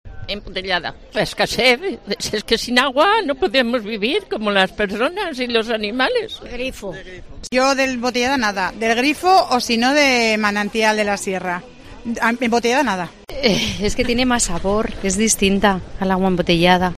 Opiniones de salmantinos sobre el agua de Salamanca